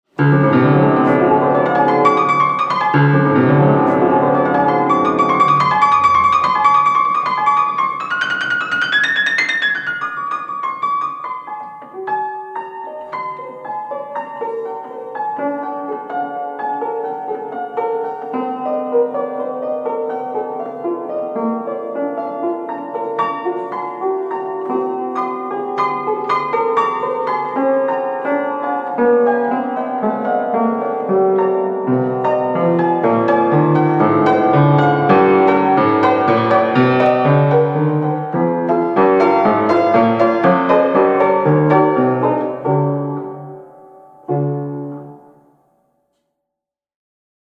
on square pianoforte, John Broadwood and Sons, 1842.